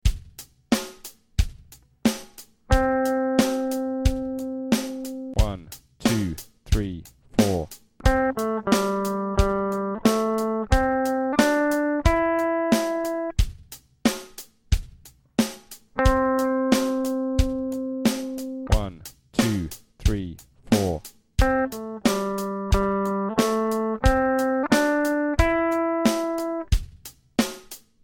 These are a 2 bar melody using the notes of the C major pentatonic or B minor pentatonic scale. The first note will be the root note and the next a descending interval. Each test will play the root note separately before the sequence.